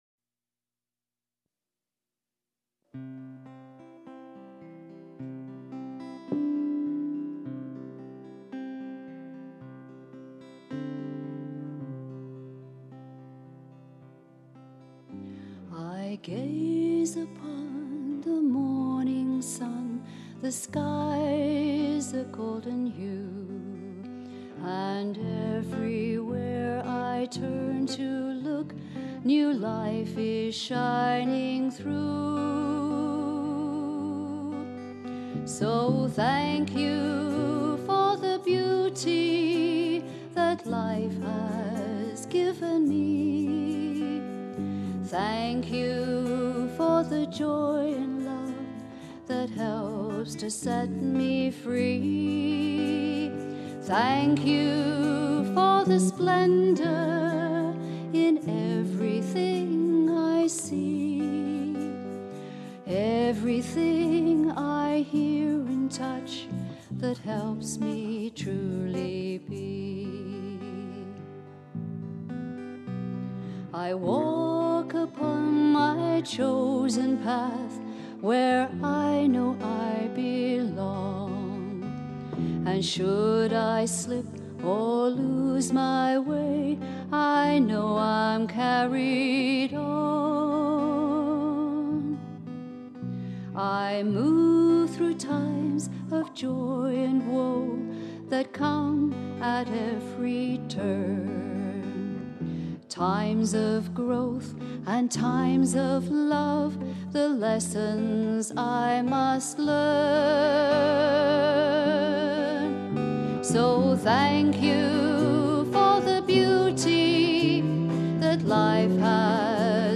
vocals and guitar
Bass
Percussion